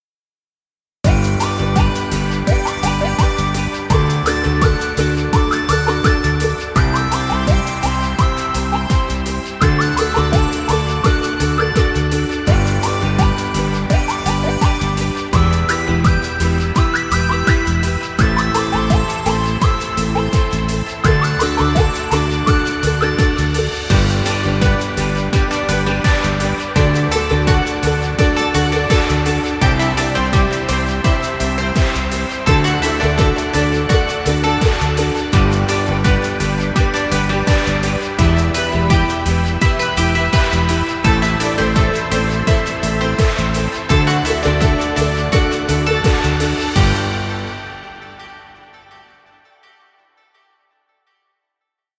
Children’s music. Background music Royalty Free.